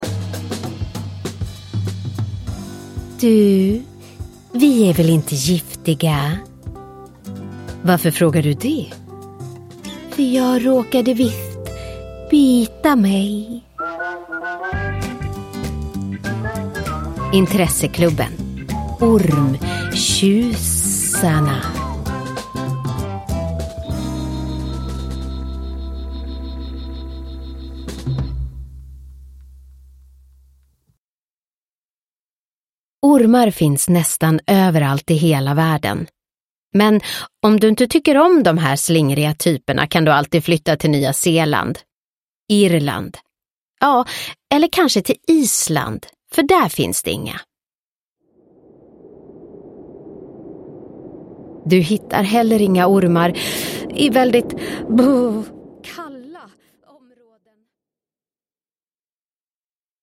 Ormtjusarna – Ljudbok – Laddas ner